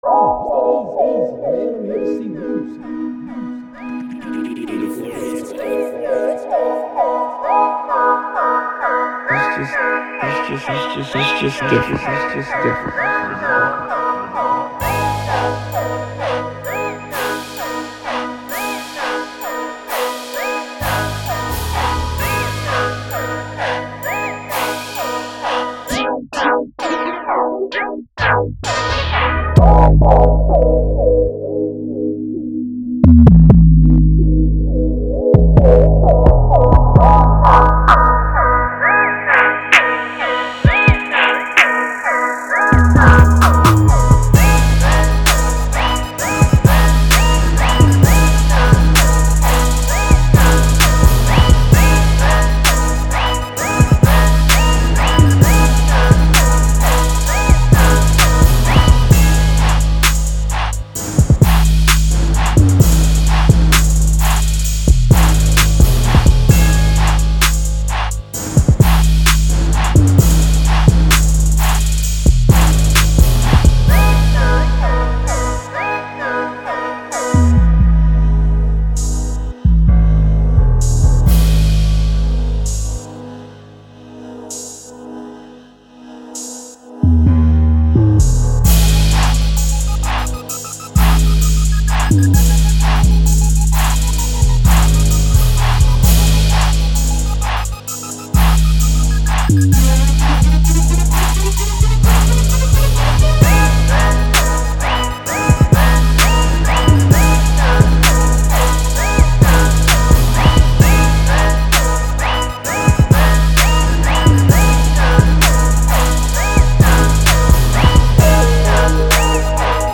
Quality Control [Trap]
beat rap trap